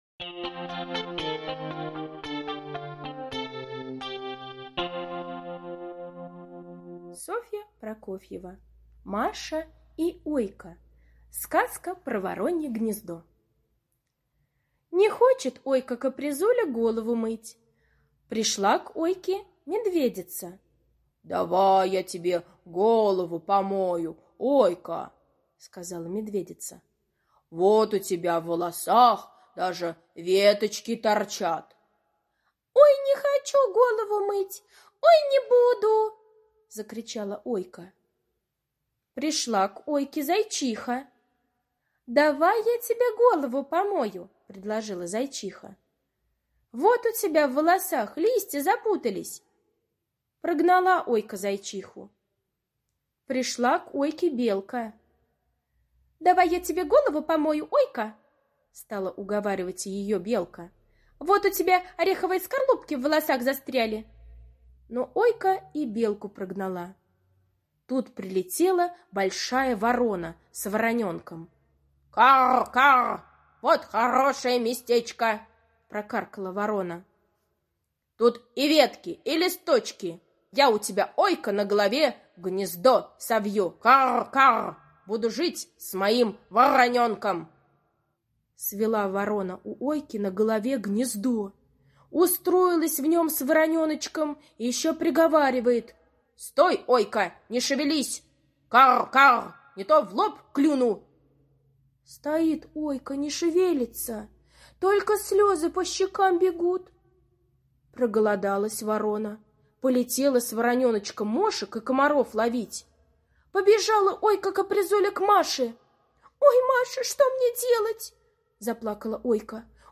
Аудиокнига в разделах